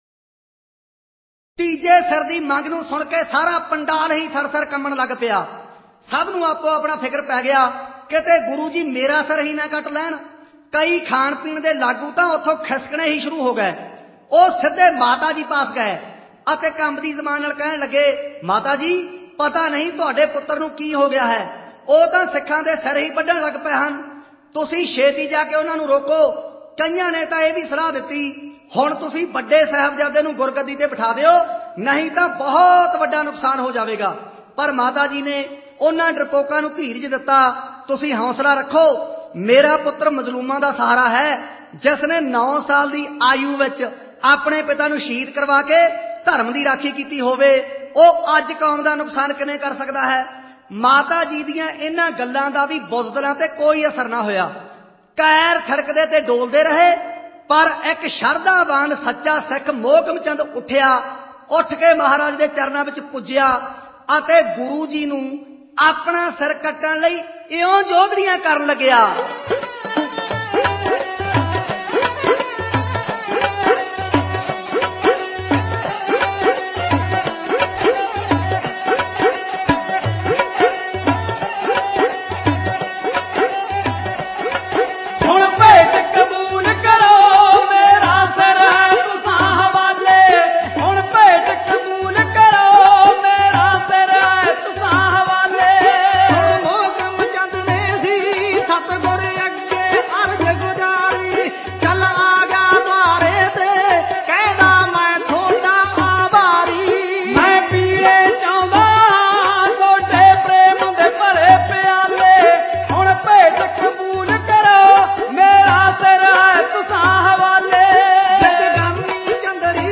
Genre: Dhadi Varan